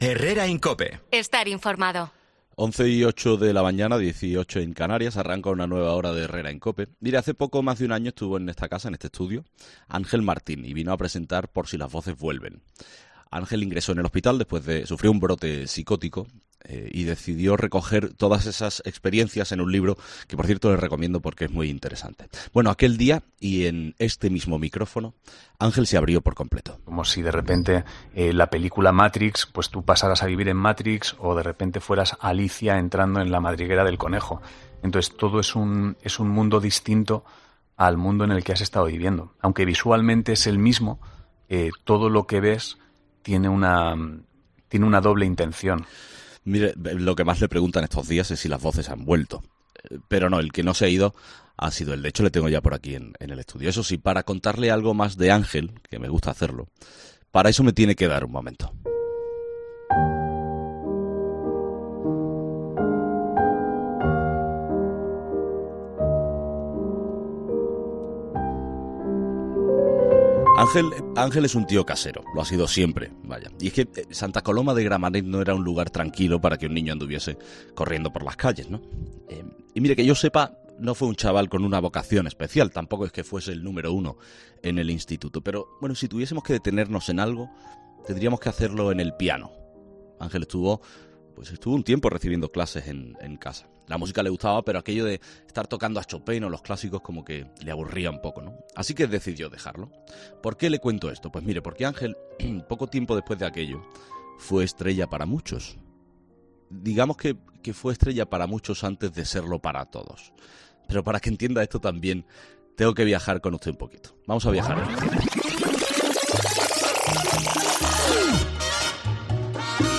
La entrevista completa de Ángel Martín en Herrera en COPE